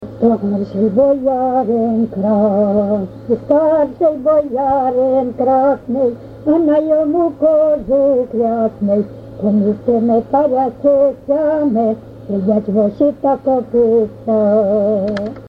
ЖанрВесільні
Місце записус. Коржі, Роменський район, Сумська обл., Україна, Слобожанщина